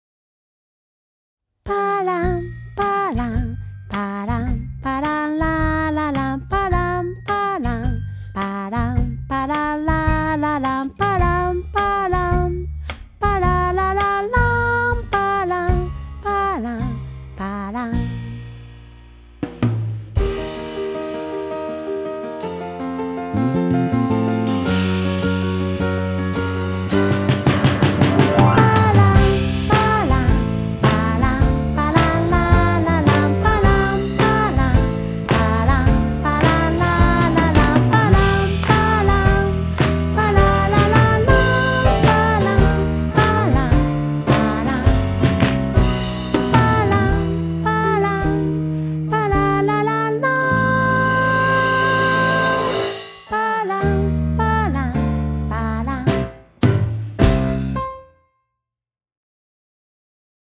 ボーカル
ギター
ベース
ピアノ
ドラム